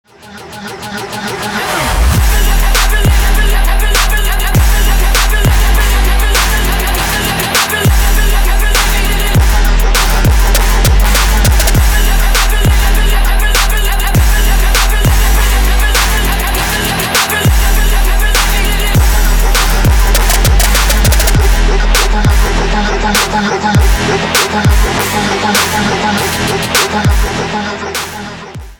• Качество: 320, Stereo
мужской голос
Electronic
Trap
быстрые